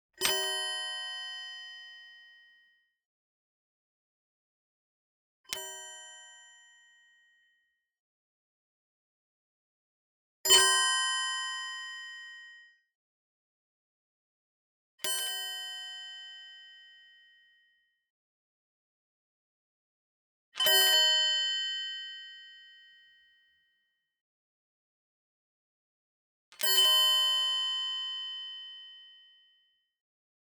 Door Bell Single Sound
horror